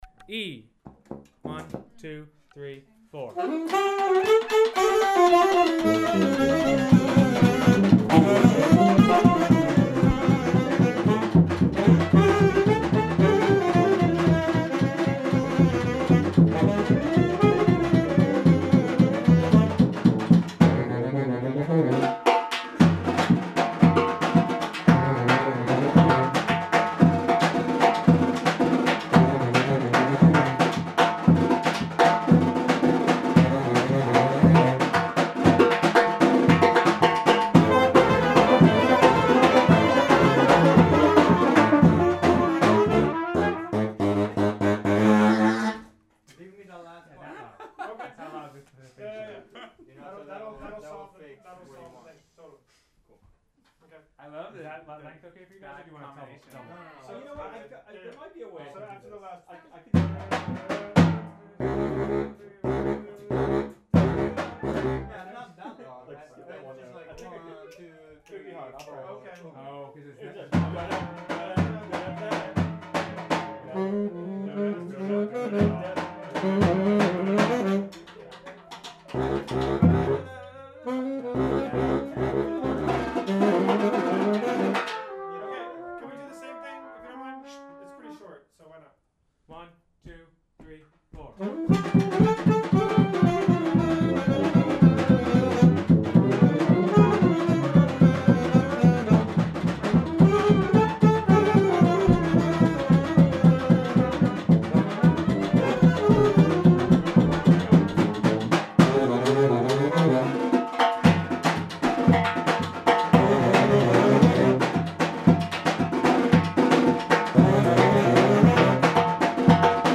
Rehearsal Recordings